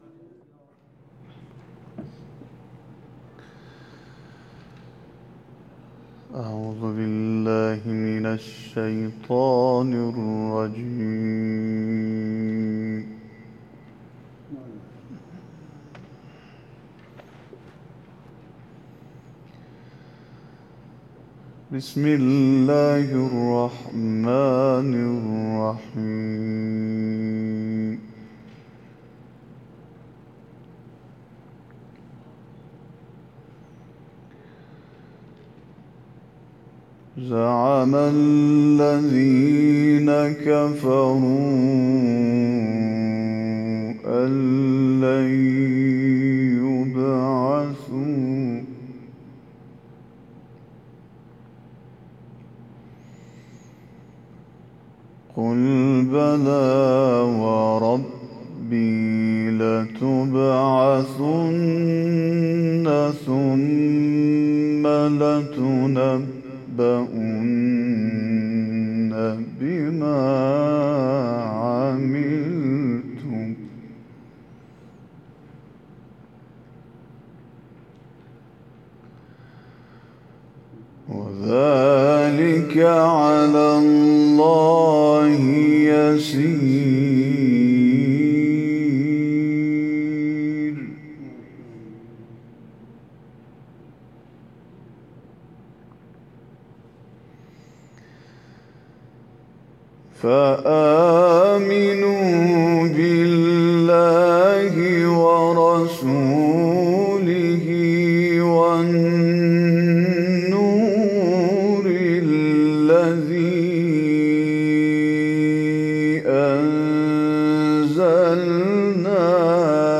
قاری ممتاز کشورمان شب گذشته و در مراسم گرامیداشت شهید ابراهیم هادی به تلاوت آیاتی از کلام‌الله مجید پرداخت.